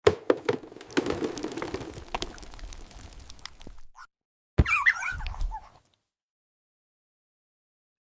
The sweetest ASMR moment 💫 sound effects free download